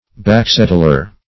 Search Result for " backsettler" : The Collaborative International Dictionary of English v.0.48: Backsettler \Back"set"tler\ (b[a^]k"s[e^]t"l[~e]r), n. [Back, a. + settler.] One living in the back or outlying districts of a community.